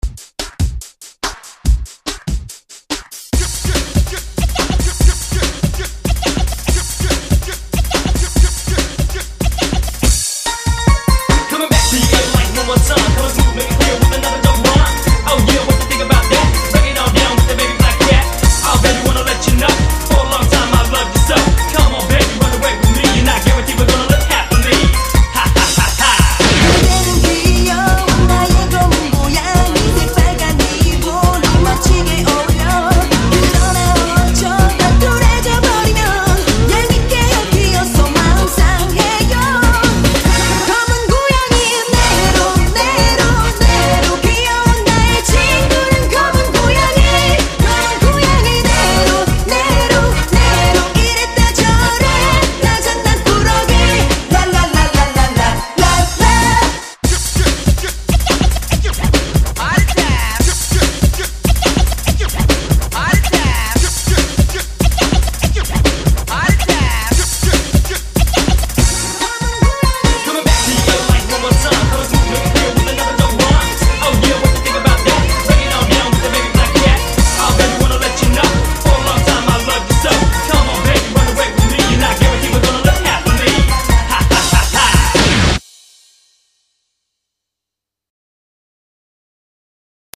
BPM143
Audio QualityPerfect (High Quality)